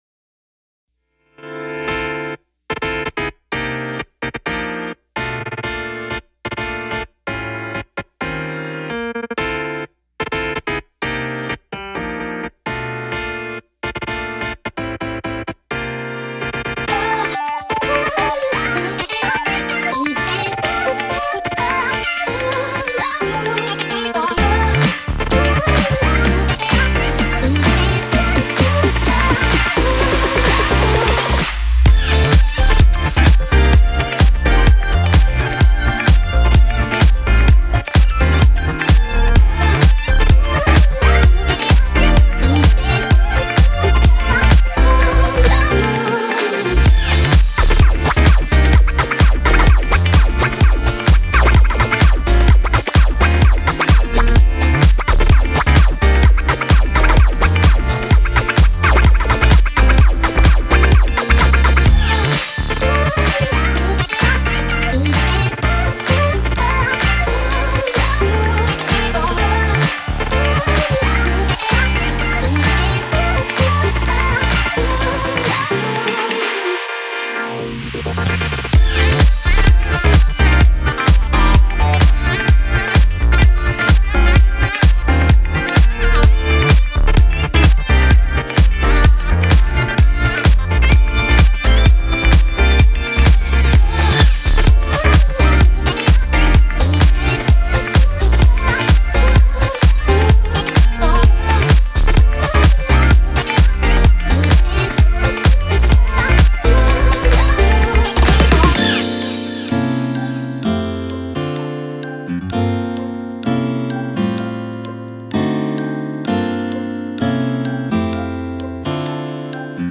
BPM128-128
Audio QualityPerfect (High Quality)
BPM 128 ♥ 2′ 18″ ♥ Future Funk